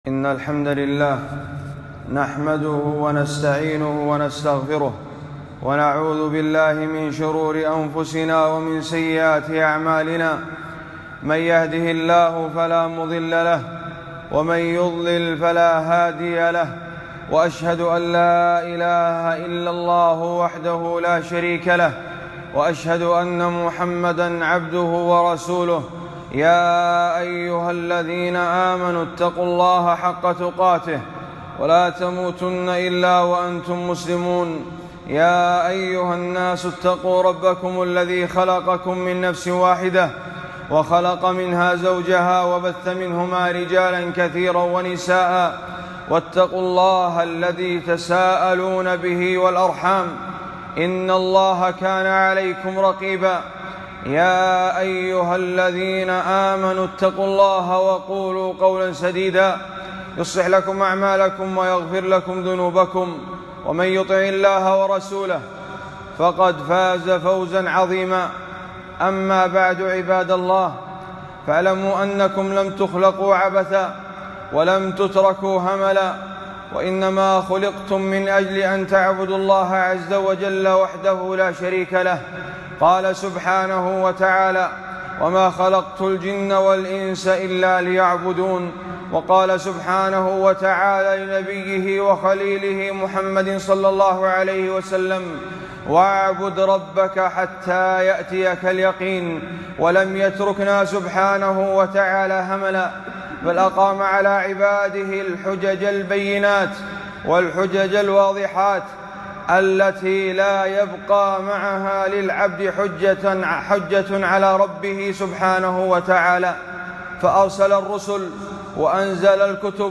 خطبة - أو لم نعمركم ما يتذكر فيه من تذكر وجاءكم النذير 5-8-1442